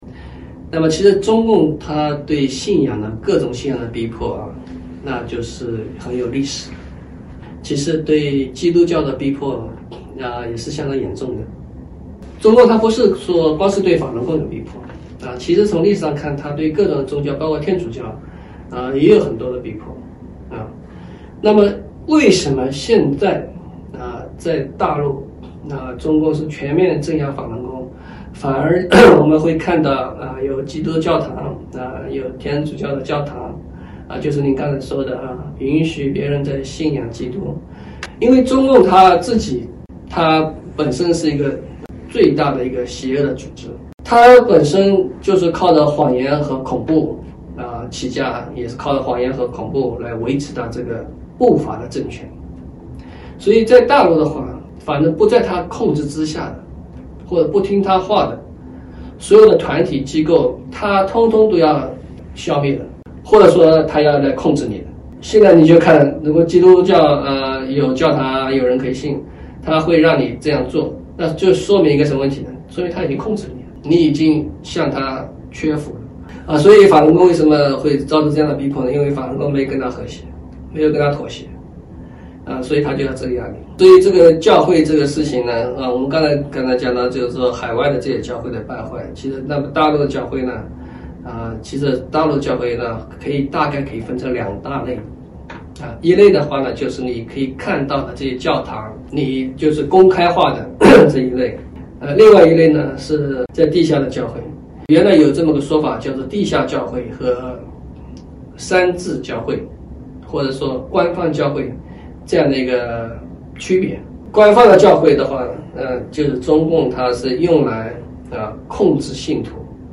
7月6日（星期二）纽约整点新闻